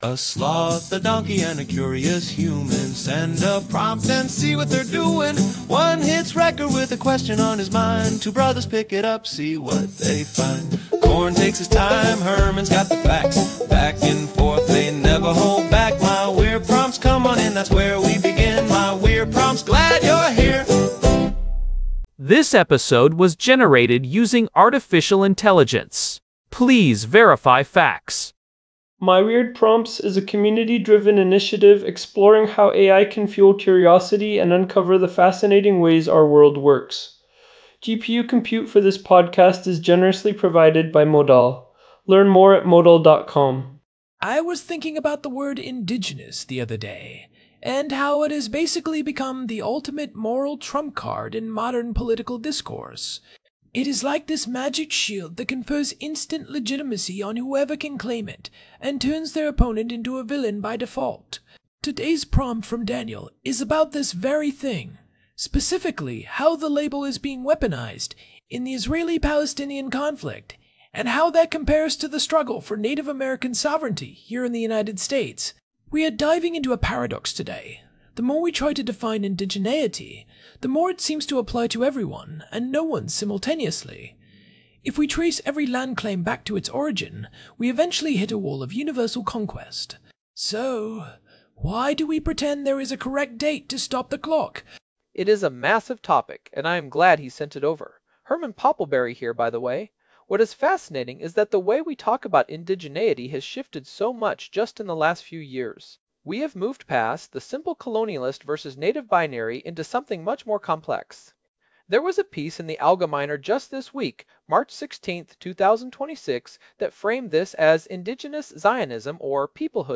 AI-Generated Content: This podcast is created using AI personas.